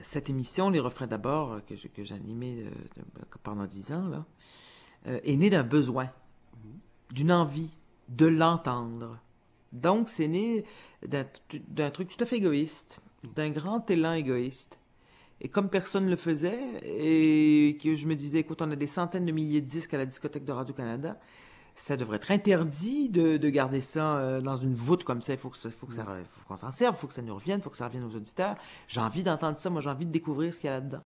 Résumé de l'entrevue :
L’intégrale de l’entrevue (en cassette DAT numérique) ainsi qu’un résumé détaillé et indexé dans la base de données de la Phonothèque sont disponibles pour la consultation au bureau de la Phonothèque québécoise.